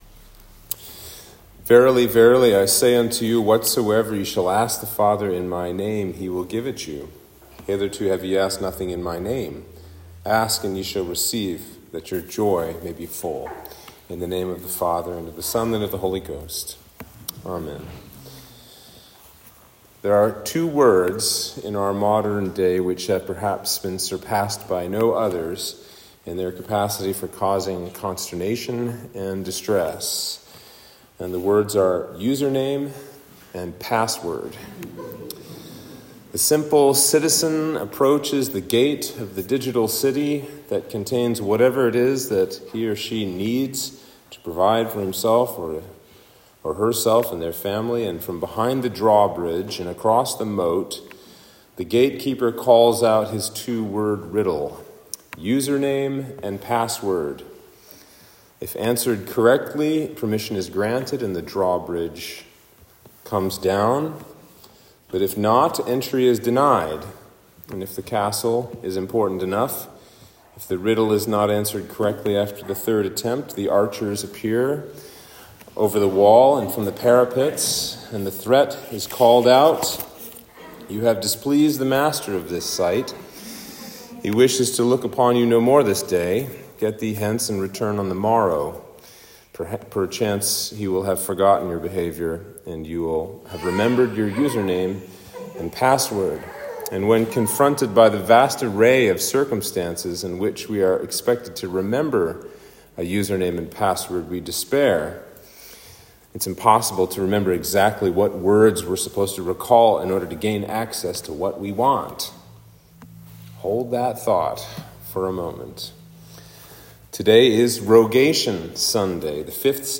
Sermon for Rogation Sunday - Easter 5